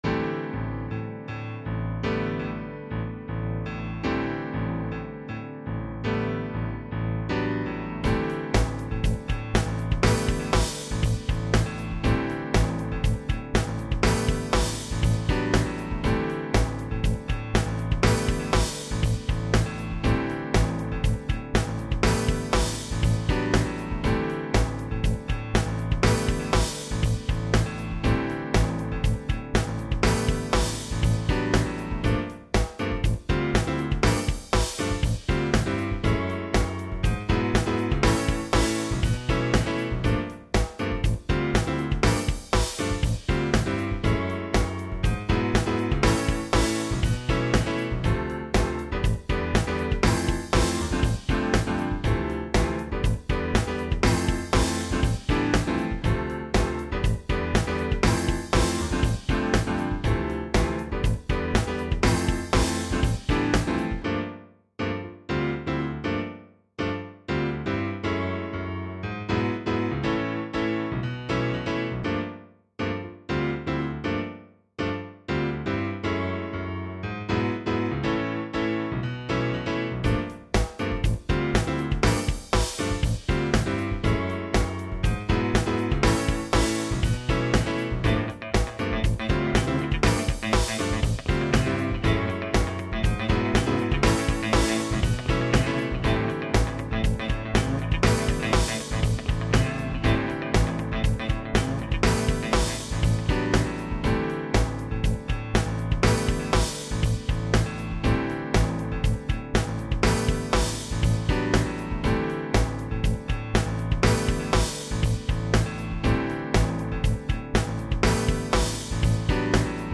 3.05MB 頭の体操的感覚で、オルガンのフレーズをメインに作りました。タイトル通り間奏っぽく、短めの曲です。